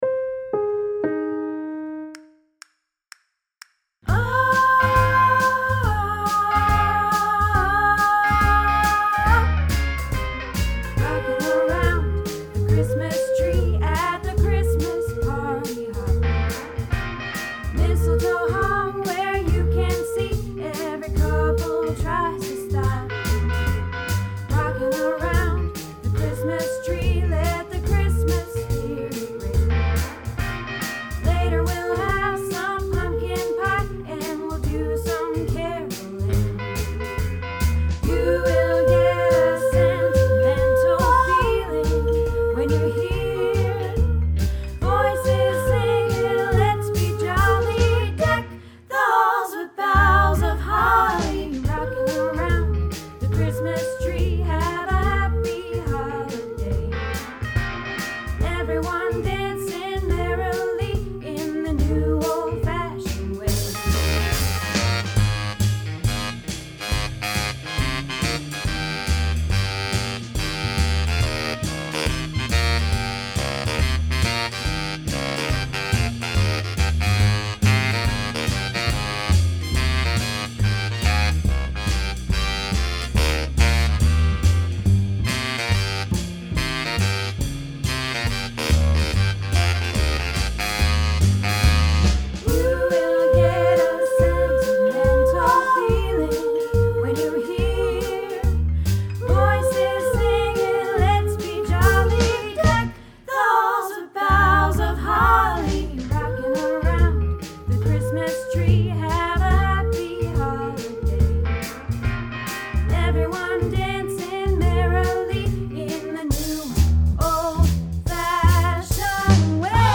Rockin Around the Christmas Tree - Soprano